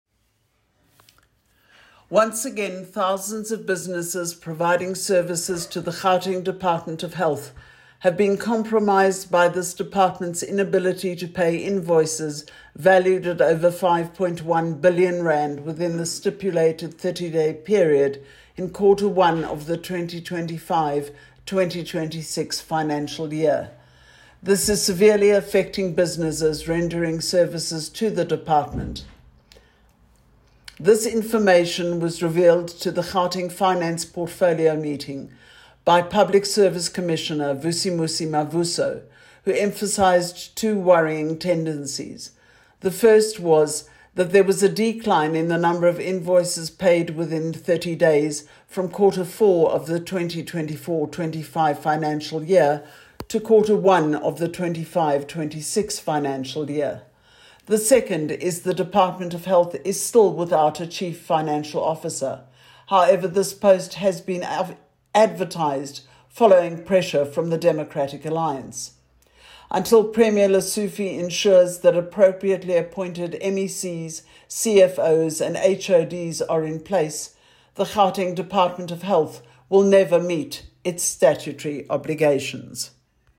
soundbite by Madeleine Hicklin MPL Spokesperson for Health